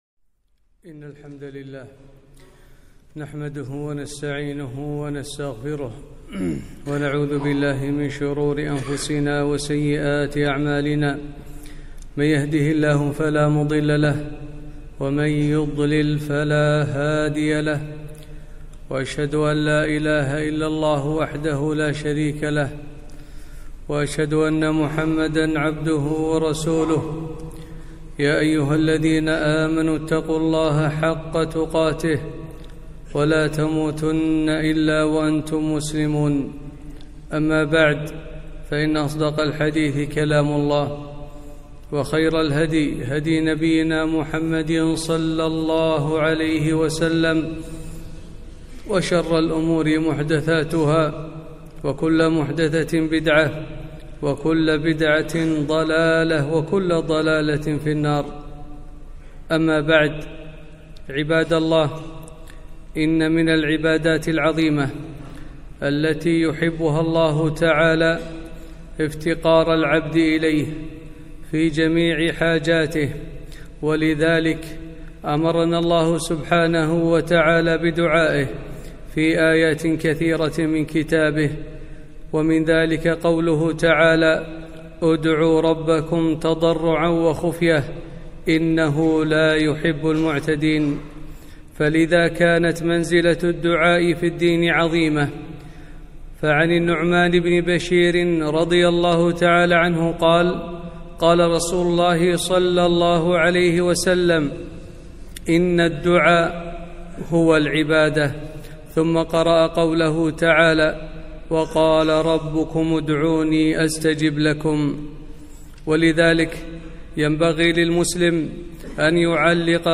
خطبة - شأن الدعاء